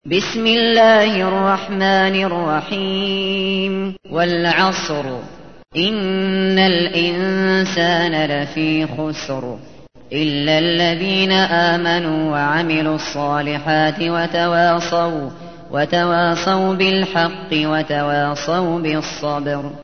تحميل : 103. سورة العصر / القارئ الشاطري / القرآن الكريم / موقع يا حسين